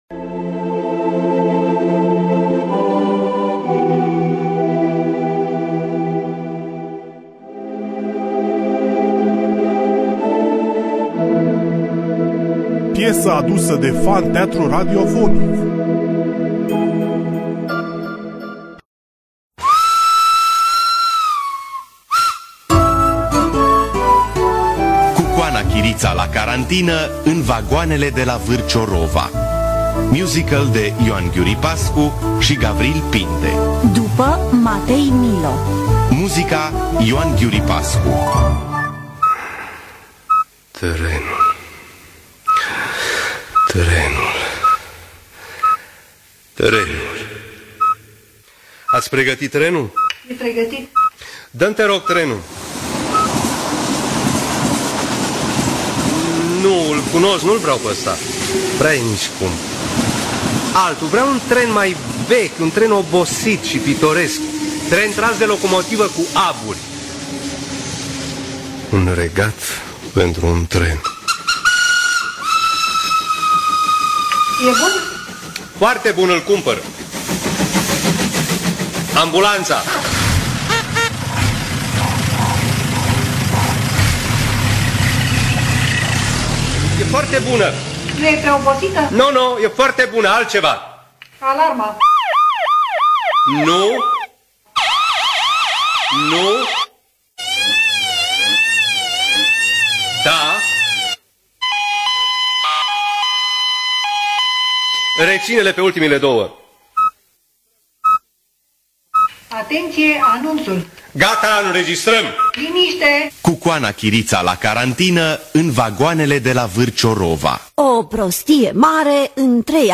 Matei Millo – Cucoana Chirita La Carantina In Vagoanele De La Varciorova (2004) – Teatru Radiofonic Online